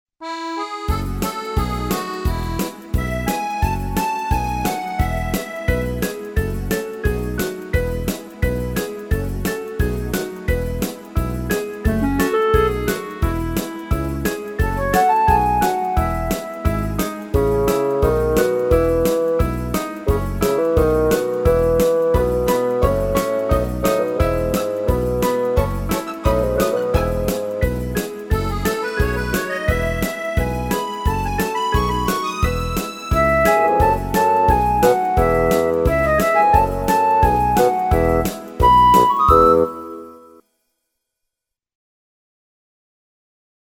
Минусовки